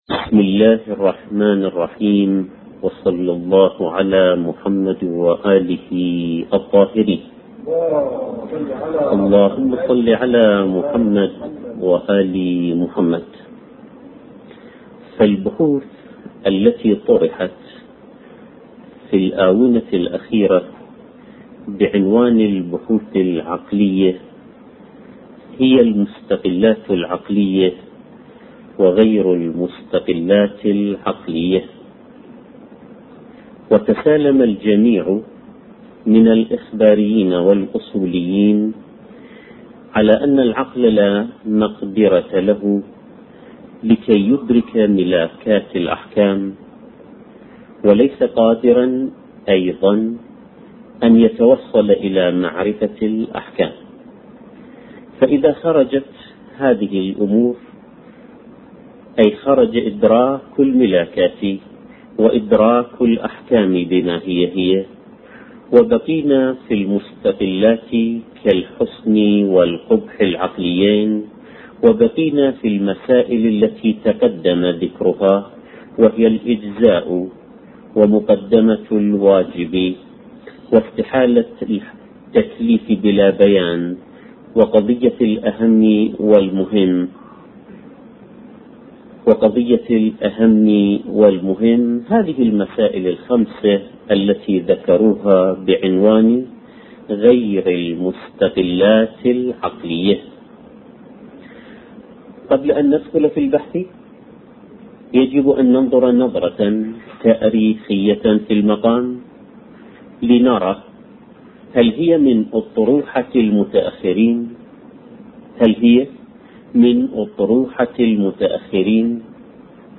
نوع: durus.